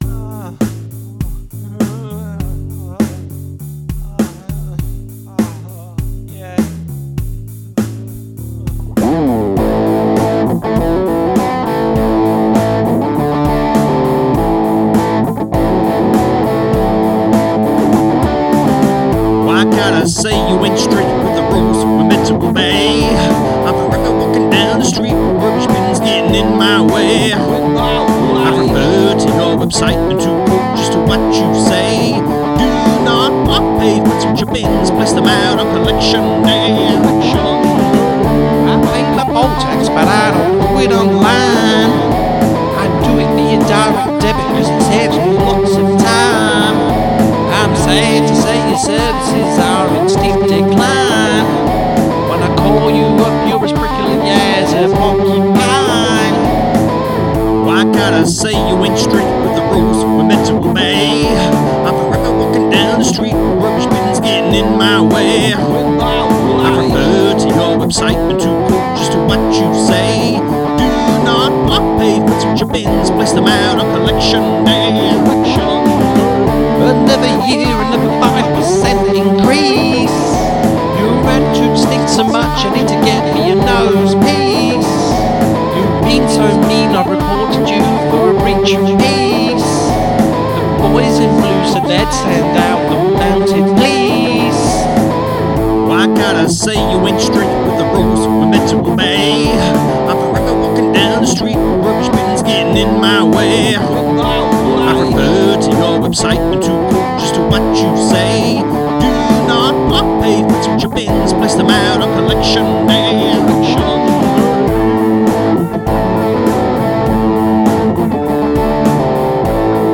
rampant romp